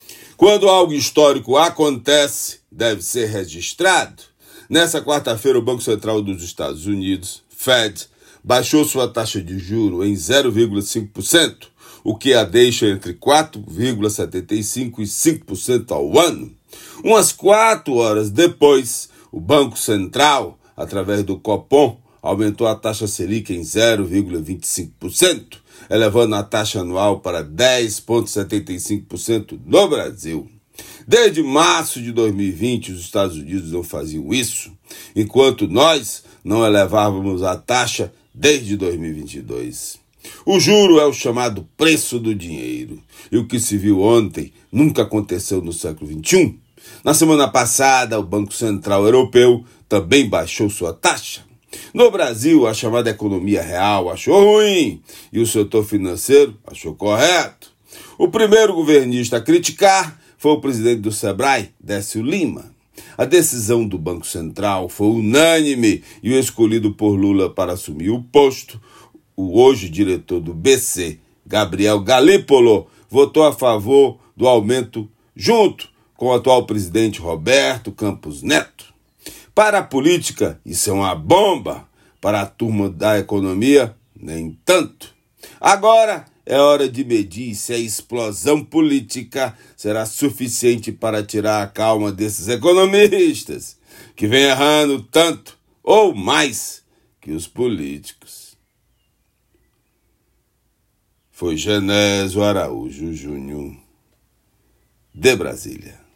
Comentário desta quinta-feira (19/09/24)
direto de Brasília.